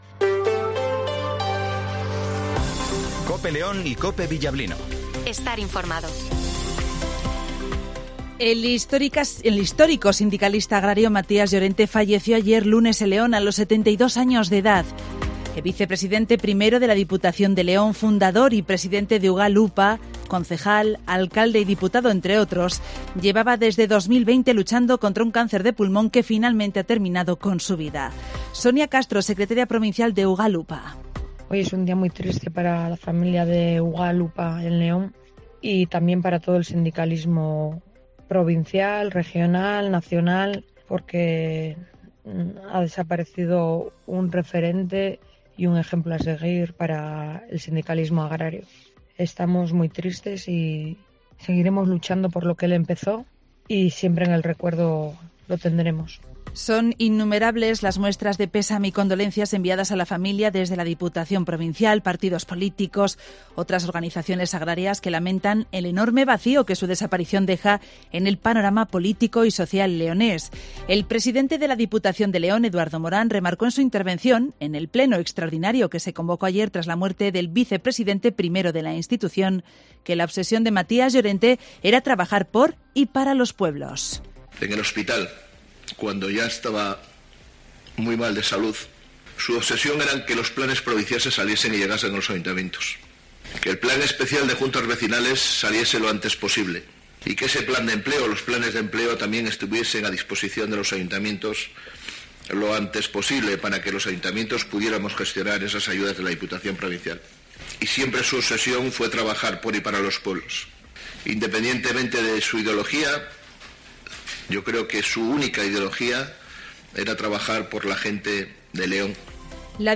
Escucha la información matinal de las 08:20 h en Cope León 30-05-23